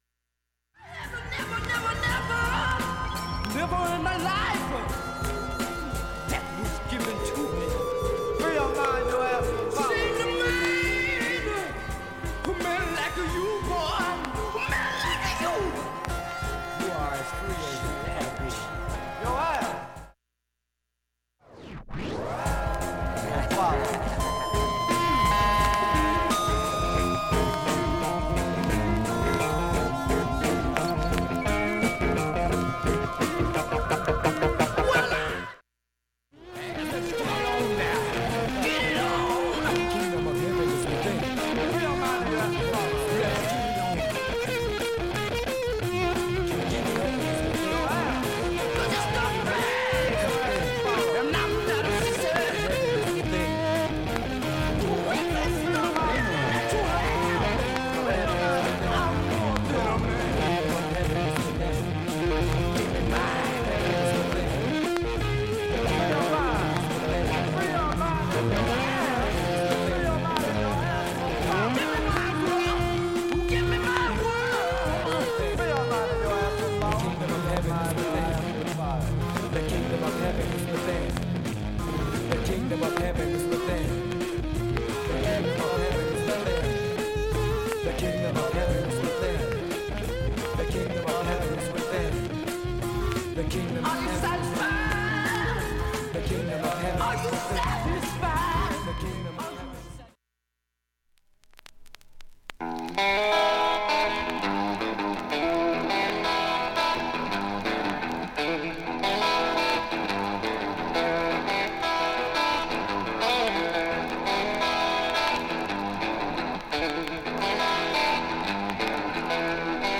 バックチリは無音部でもかすかで
SHURE M 44G 針圧３グラムで
針飛びはありませんでした。
細かいプツなどもかすかなレベルです
1,A-1序盤かすかなプツが9回と3回出ます。
3,(1m41s〜)B-1始めに16回プツ出ます。
単発のかすかなプツが２１箇所